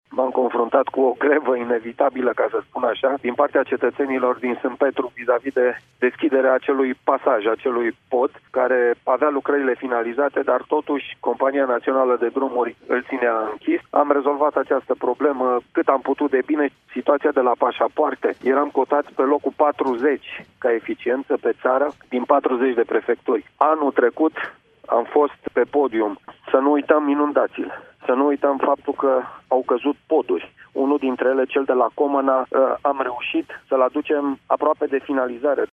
Acesta a declarat pentru Radio România Brașov FM că cei doi ani în care a exercitat funcția de prefect au fost plini de evenimente: